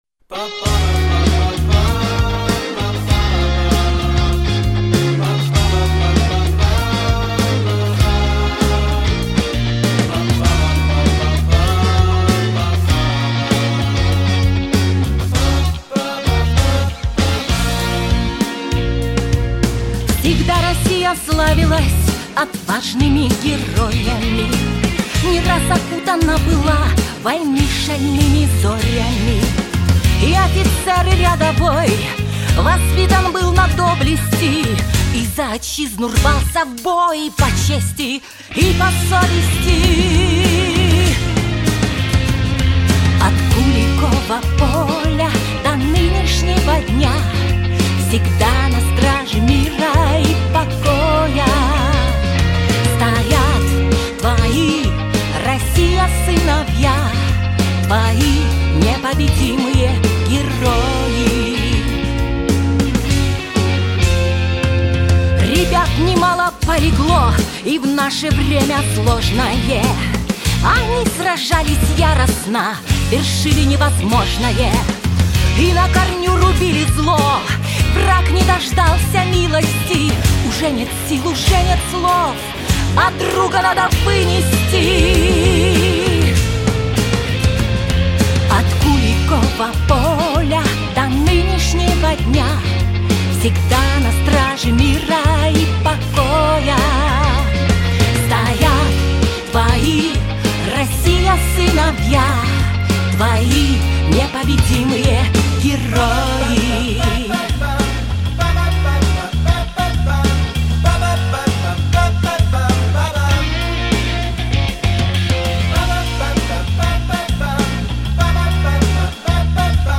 • Качество: Хорошее
• Жанр: Детские песни
патриотическая